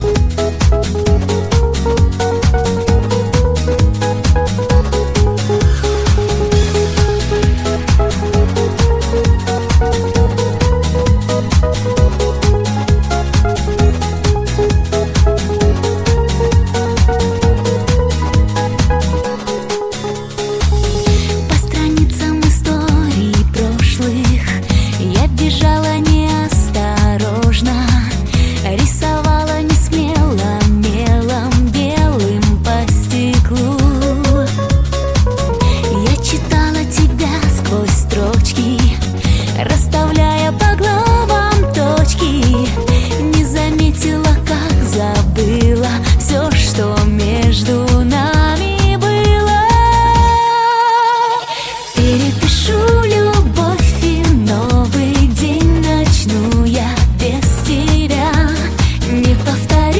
Pop
44.1 kHz, Stereo